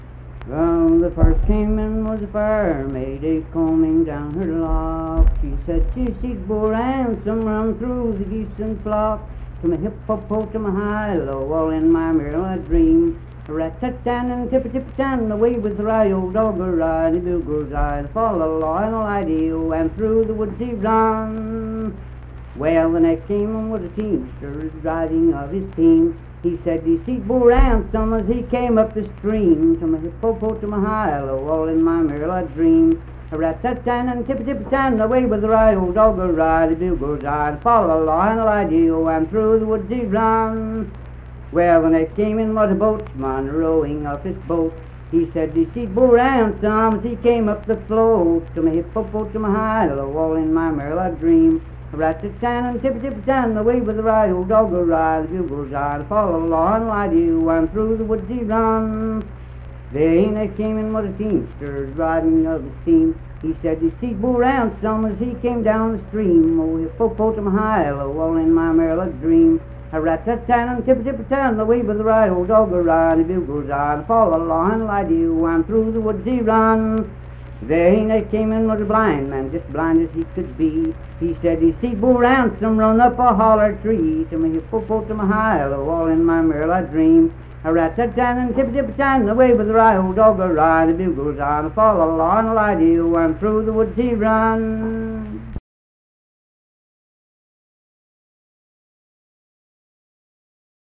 Unaccompanied vocal music
Verse-refrain 5(4)&R(6).
Voice (sung)
Mingo County (W. Va.), Kirk (W. Va.)